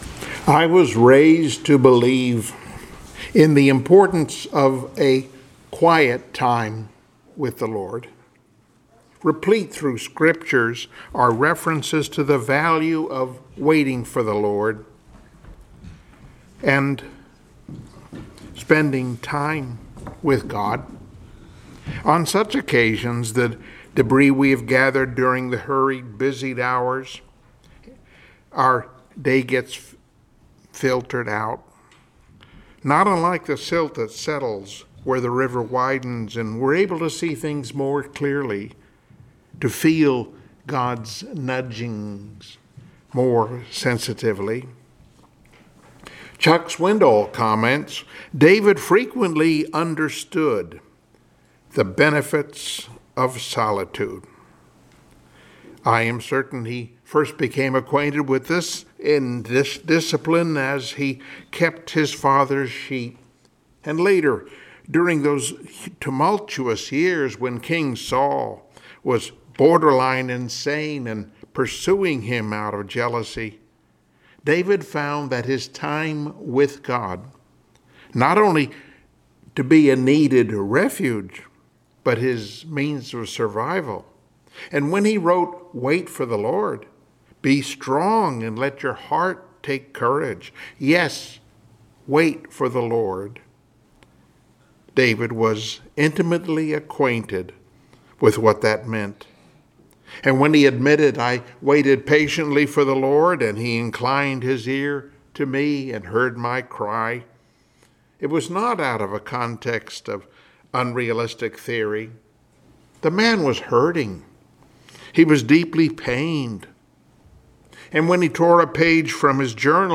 Passage: Mathew 6:9 Service Type: Sunday Morning Worship